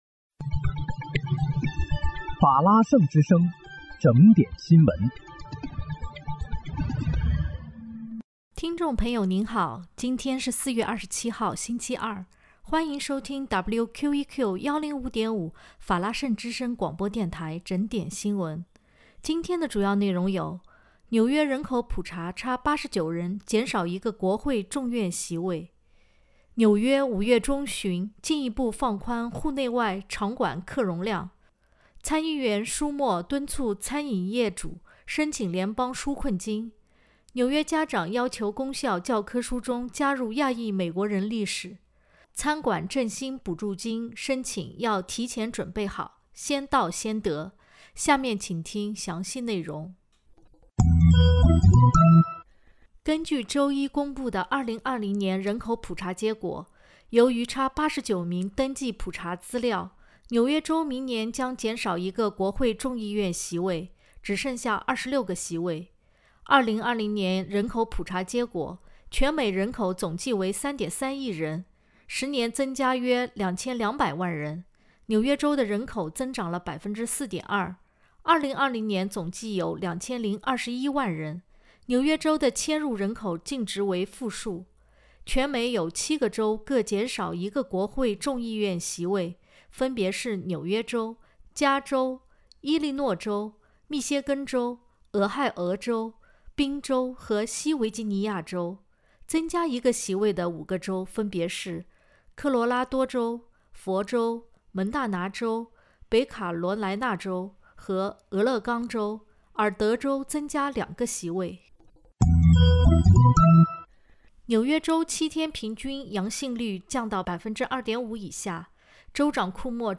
4月27日（星期二）纽约整点新闻